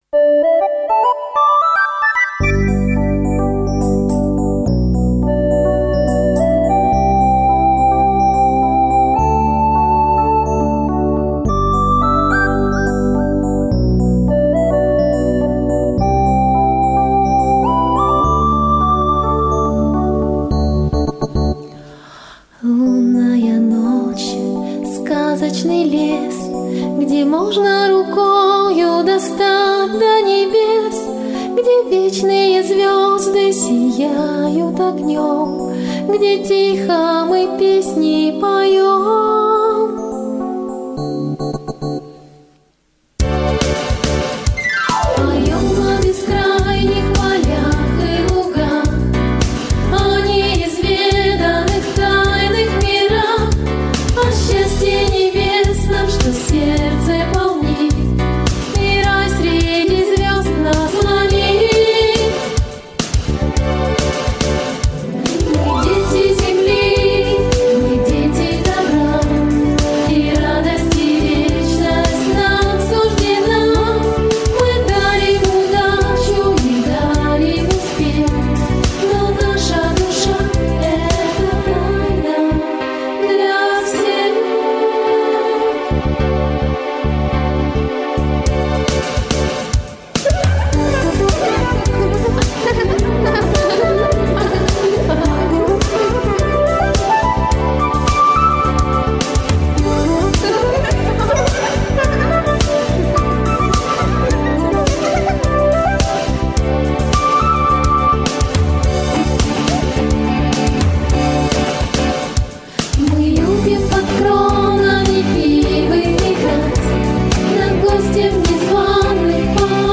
• Категория: Детские песни
🎶 Детские песни